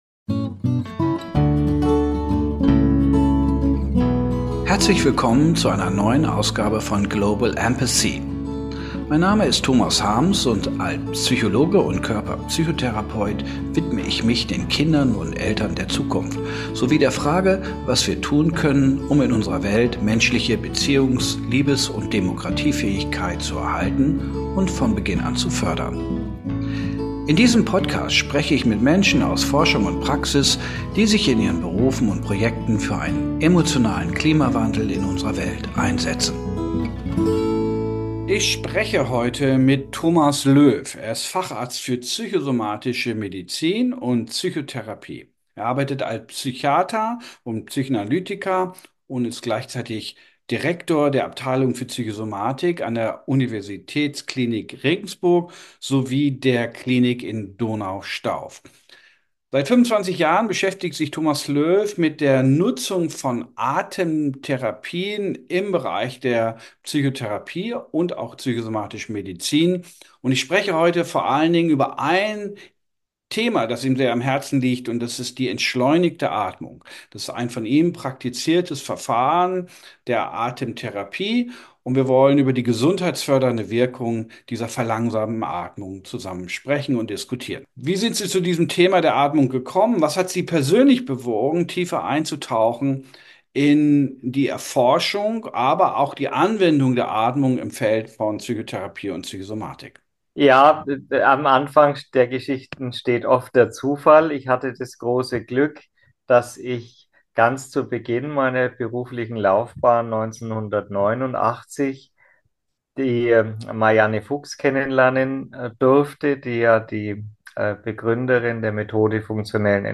Join us for an inspiring and transformative conversation